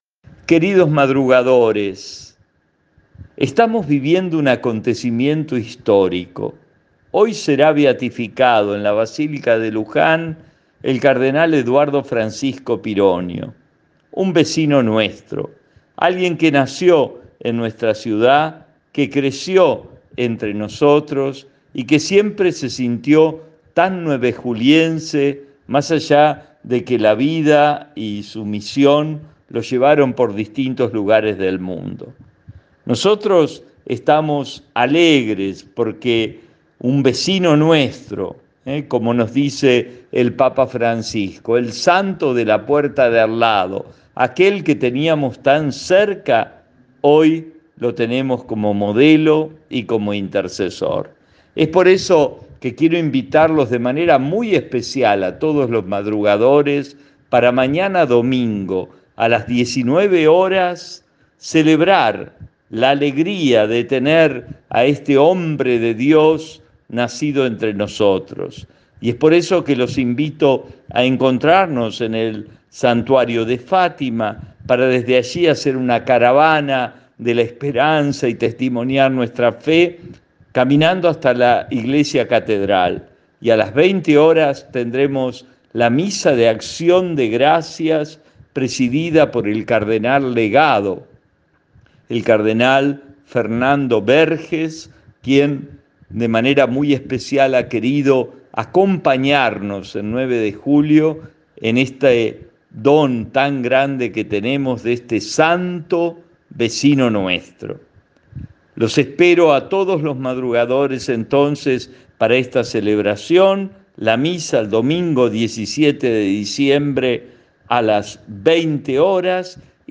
Un grupo de 40 varones lo hicieron en una de las naves de la catedral nuevejuliense donde el centro de las meditaciones entre cada una de los misterios fueron meditaciones del cardenal Eduardo Pironio, ante su ceremonia de beatificación, más tarde en Luján.